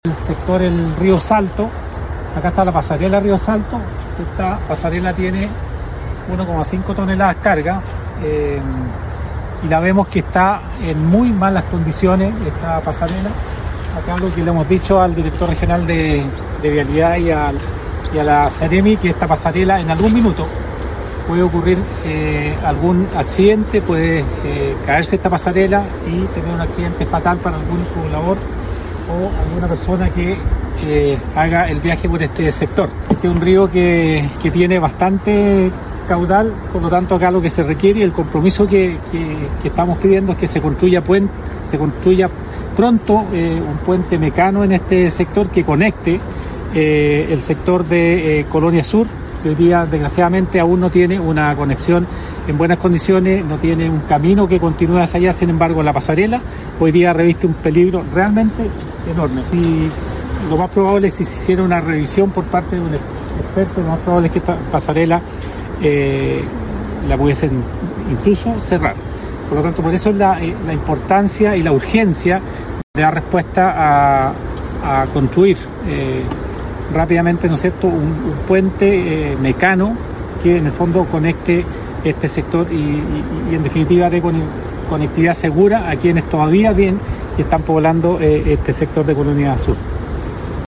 Alcalde de Cochrane